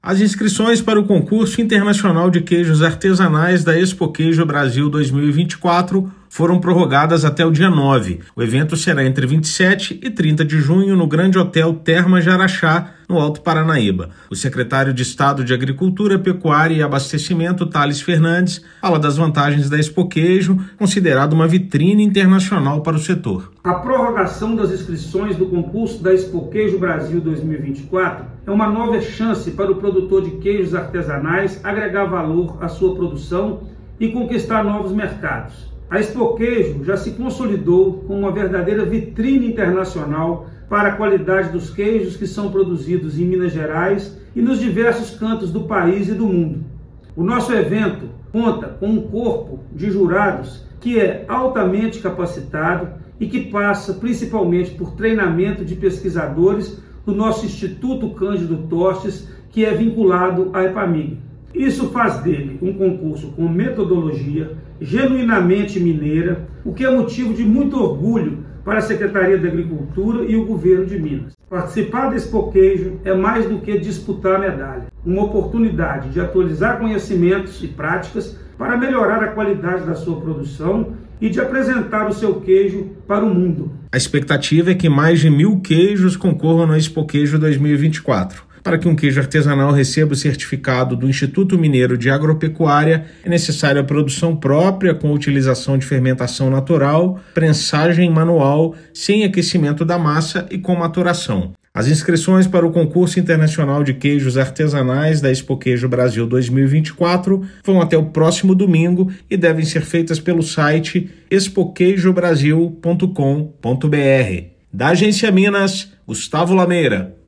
[RÁDIO] Prorrogadas as inscrições para o Concurso Internacional da Expoqueijo 2024
Os produtores têm até domingo (9/6) para inscrever os queijos artesanais na disputa que começa no dia 27/6, em Araxá. Ouça a matéria de rádio: